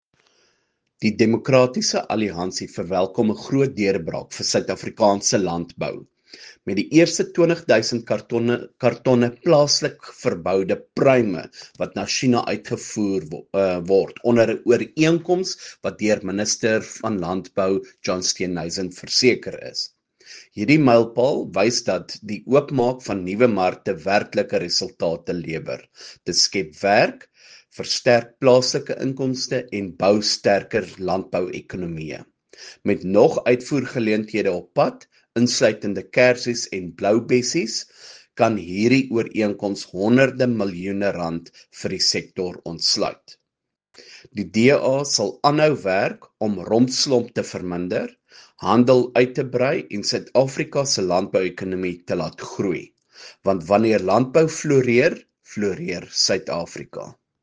Issued by Beyers Smit MP – DA Spokesperson for Agriculture
Attention broadcasters: Attached here are audio clips by Beyers Smit MP in